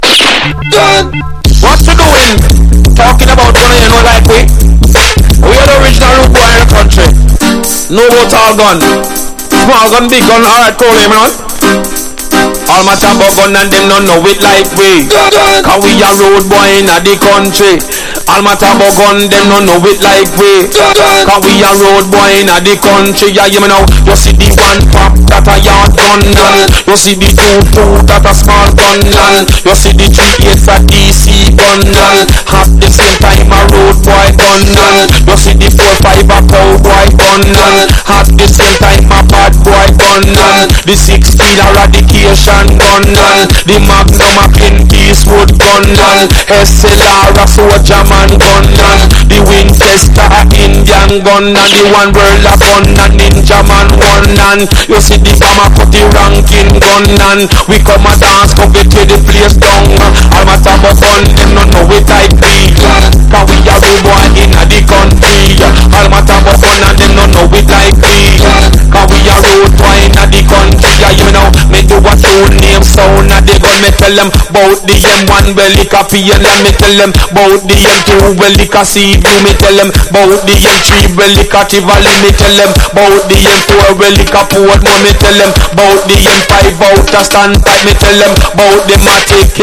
# DANCE HALL